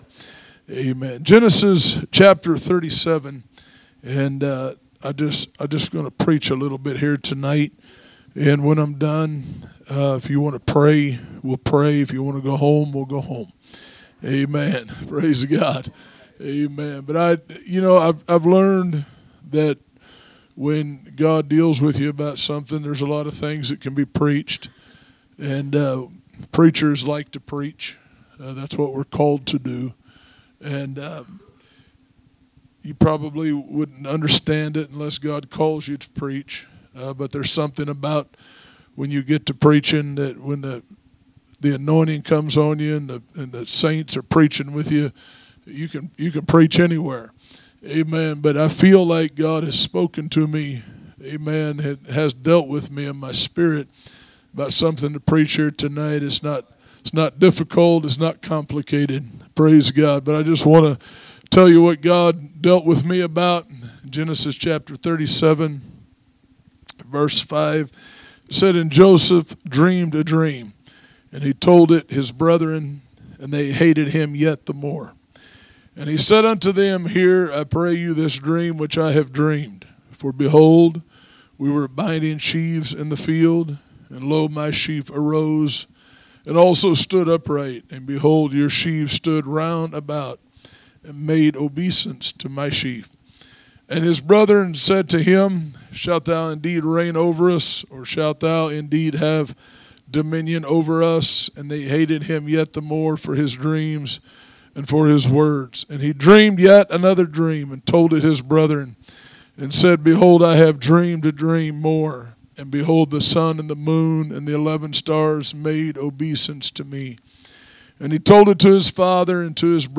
Sunday Evening Message
From Series: "Guest Speakers"